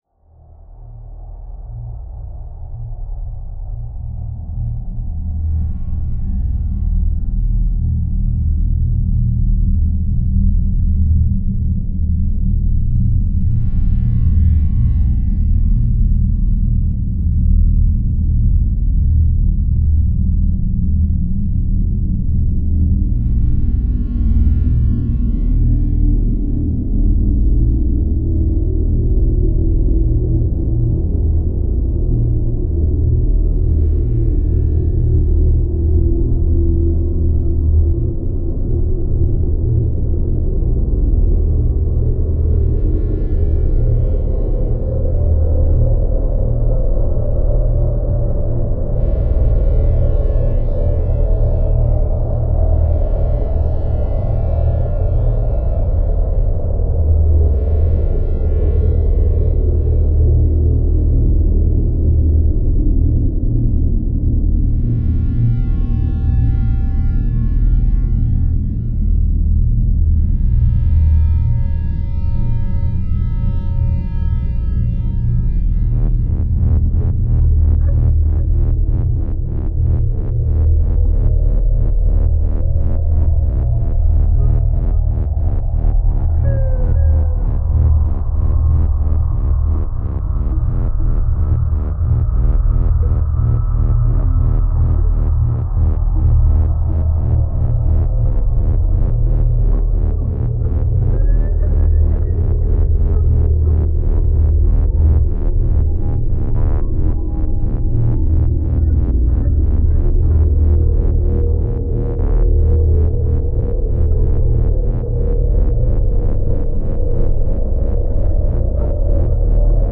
Genre: Drone.